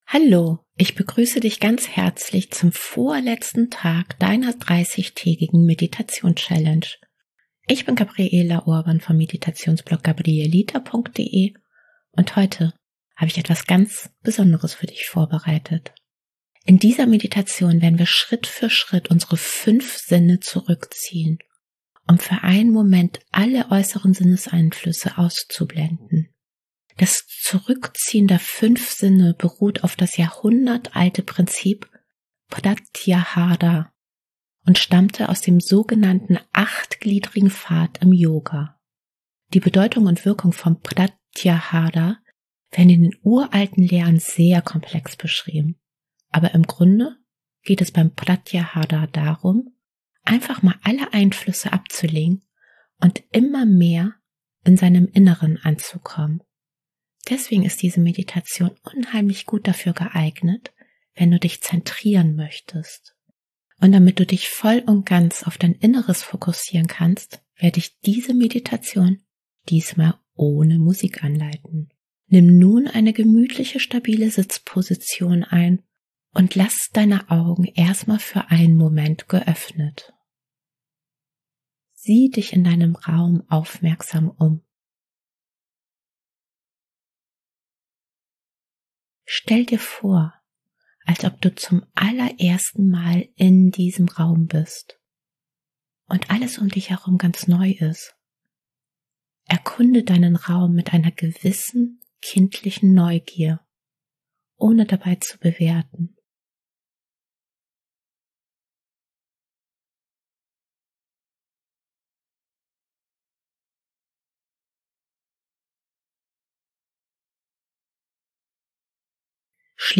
Und damit du dich voll und ganz auf dein Inneres fokussieren kannst, werde ich diese Meditation Pratyahara diesmal ohne Musik anleiten.